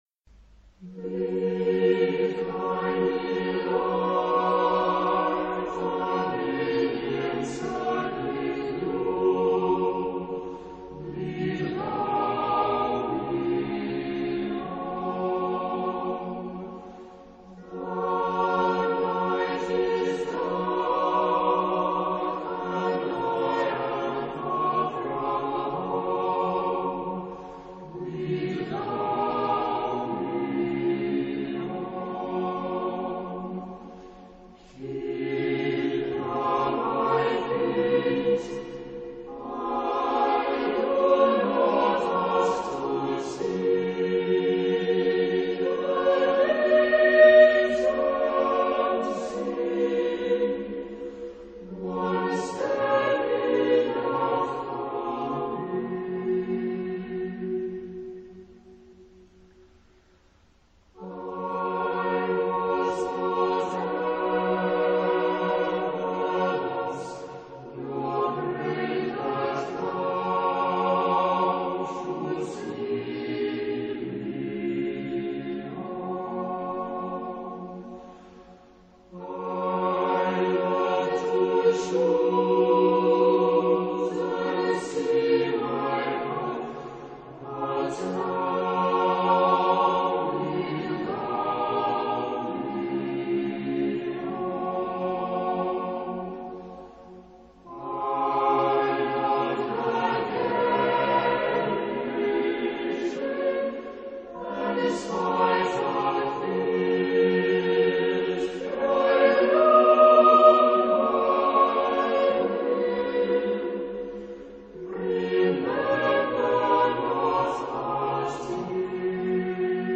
choir.mp3